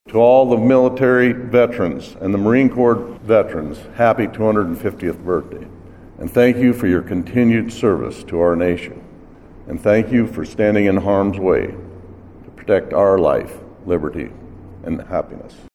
PIERRE, S.D.(DRGNews)-The United States Marine Corps turned 250 years old Monday and South Dakota marked the occasion with a ceremony and official cake cutting in Pierre.